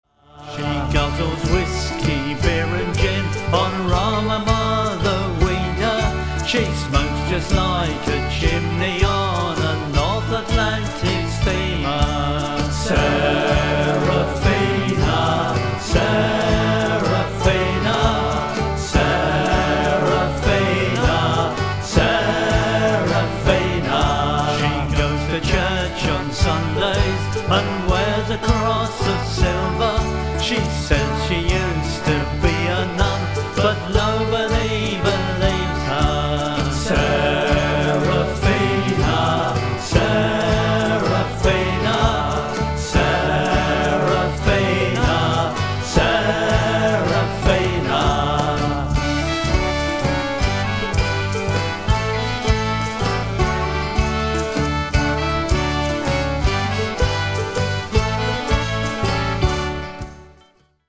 A traditional sea shanty.
I’ve also changed the structure so that the two verse lines are run together and the responses run together afterwards.